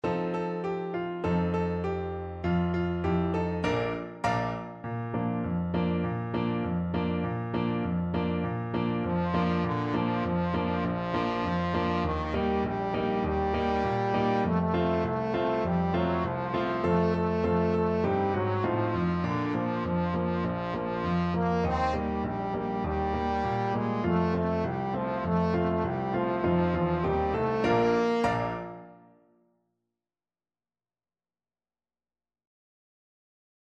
Trombone
2/2 (View more 2/2 Music)
D4-C5
Bb major (Sounding Pitch) (View more Bb major Music for Trombone )
Raucous, two in a bar =c.100
Traditional (View more Traditional Trombone Music)